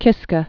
(kĭskə)